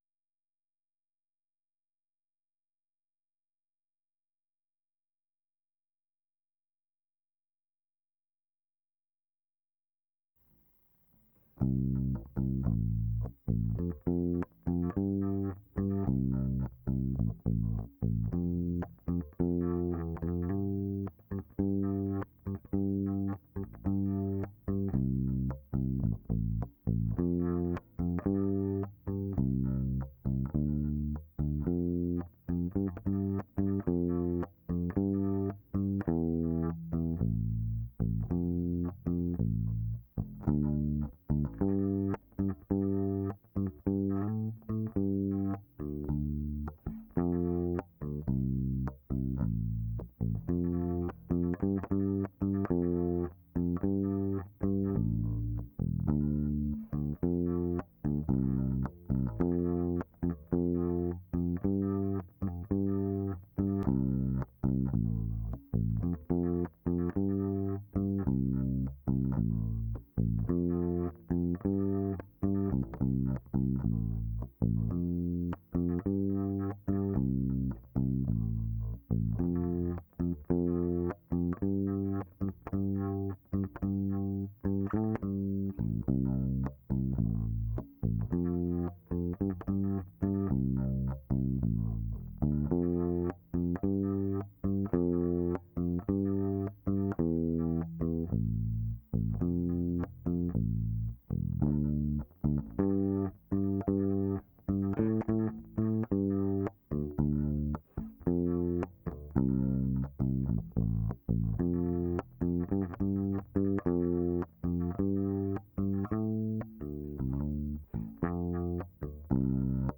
basstake2.wav